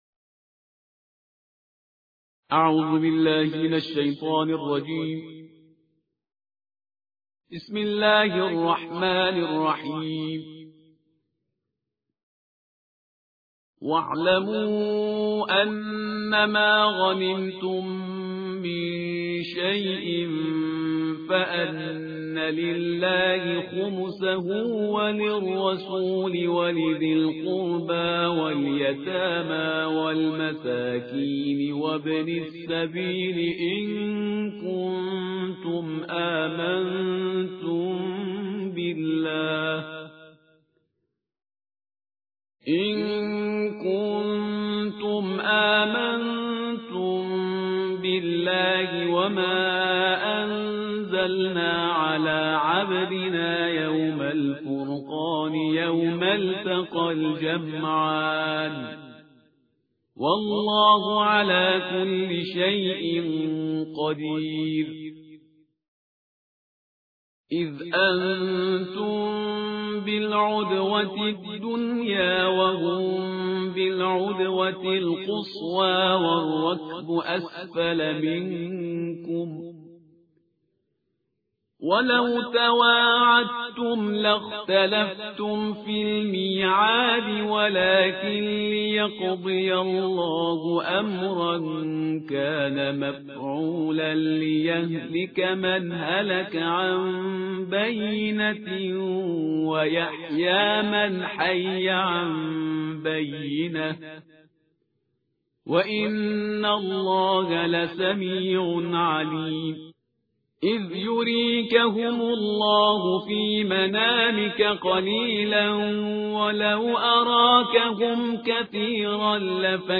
ترتیل جزء ده قرآن کریم